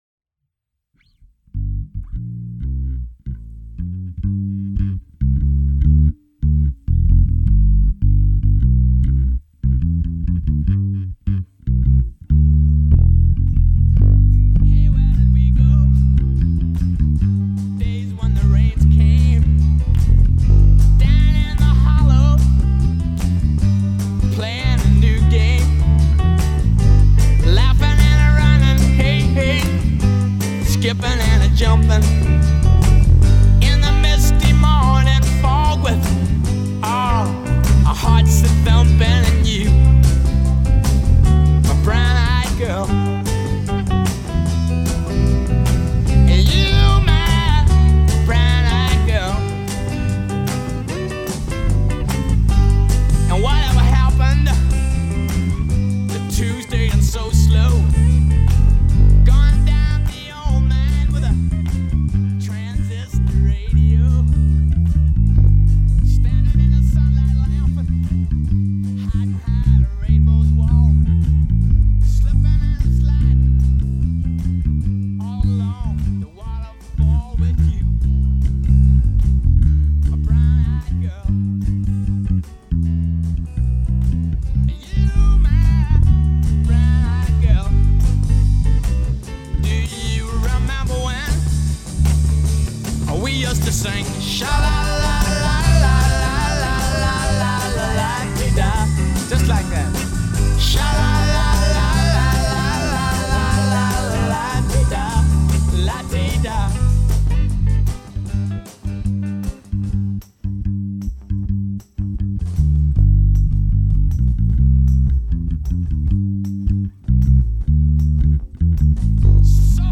Random Bass Isolation